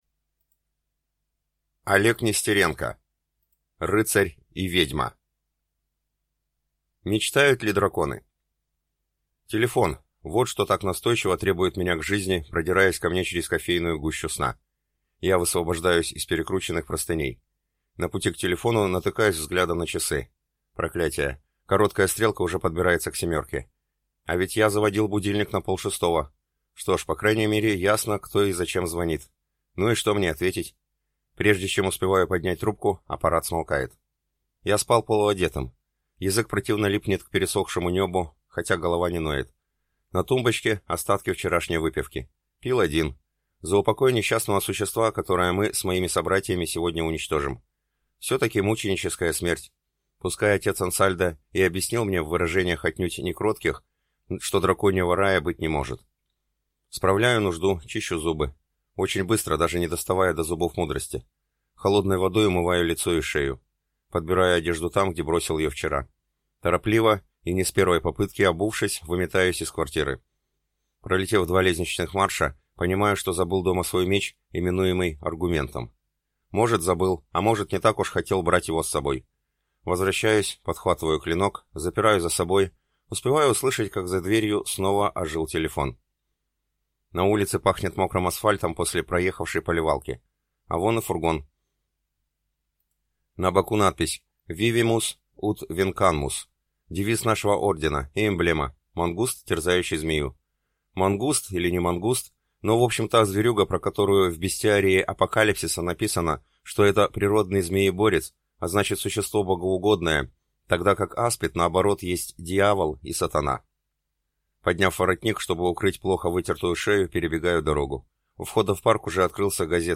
Аудиокнига Рыцарь и ведьма | Библиотека аудиокниг